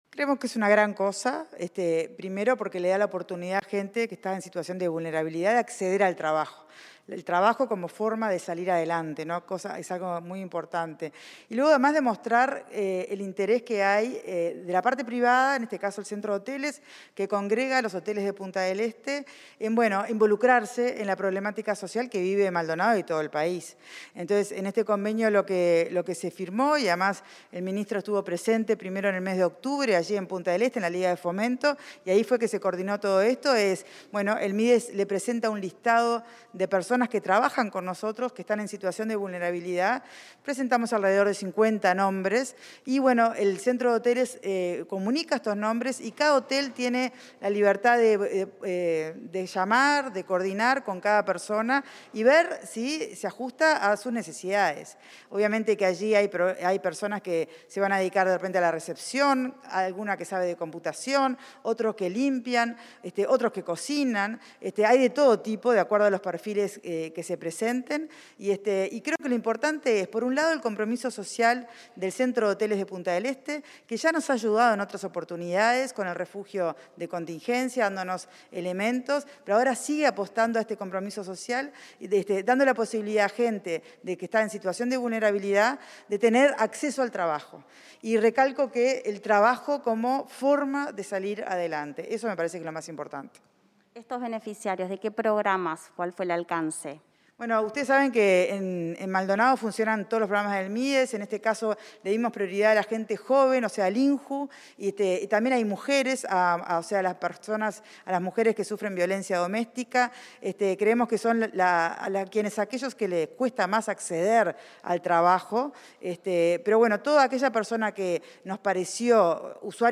Entrevista a la directora departamental del Mides en Maldonado, Magdalena Zumarán